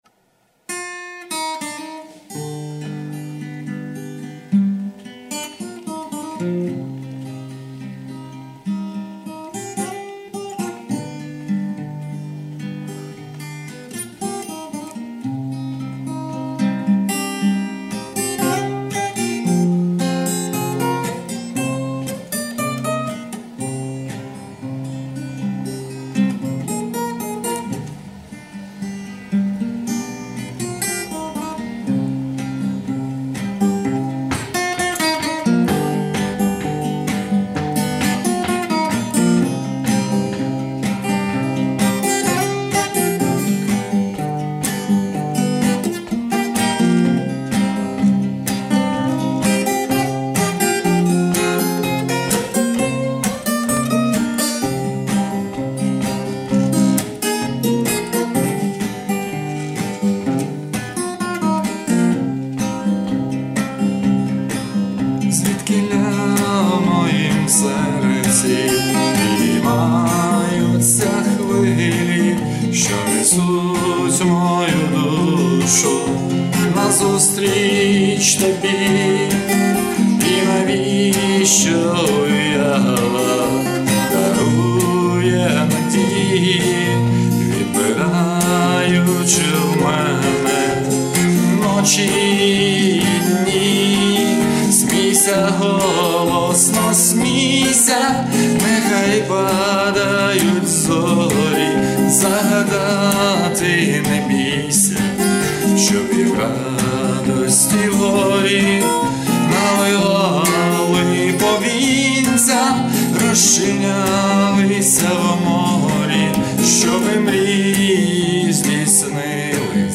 Рубрика: Поезія, Авторська пісня